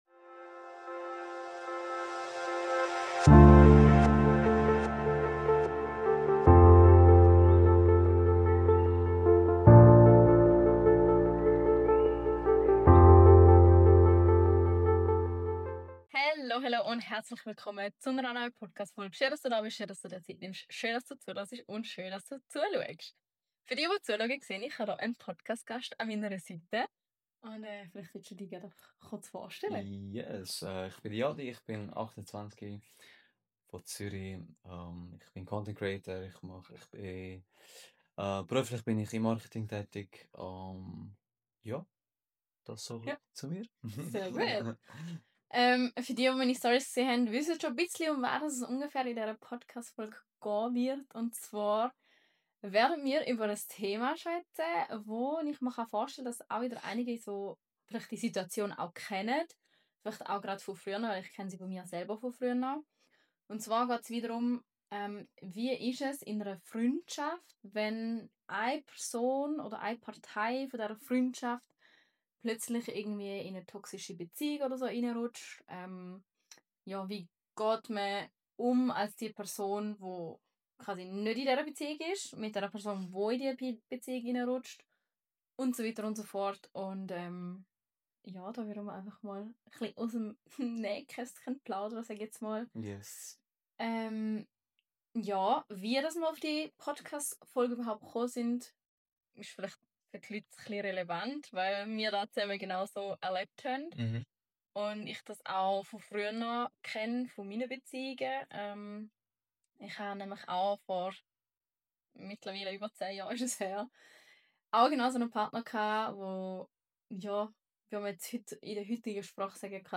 In dieser Folge spreche ich mit einem Freund über unsere eigene Geschichte – wie eine Freundschaft fast an Kontrolle, Eifersucht und Distanz zerbrochen wäre. Wir sprechen über Verlust, Rückkehr, Heilung und den Mut, sich selbst wiederzufinden.